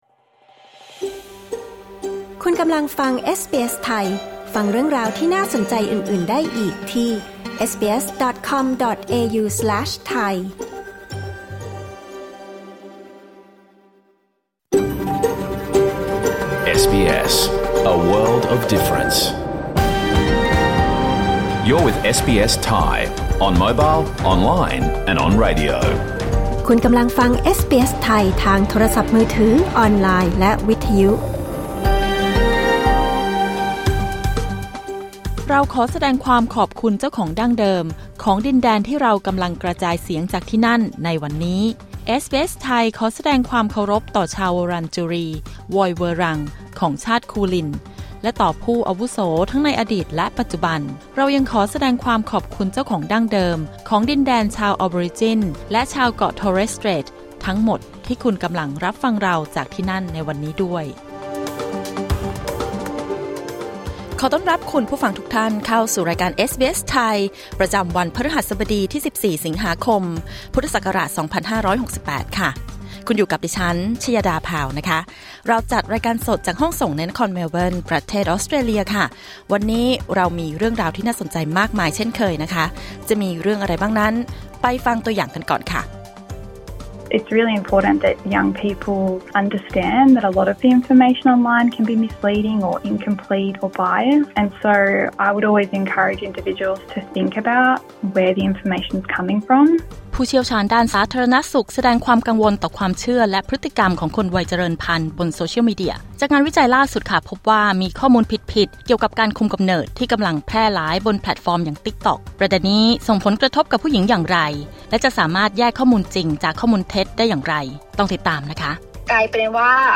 รายการสด 14 สิงหาคม 2568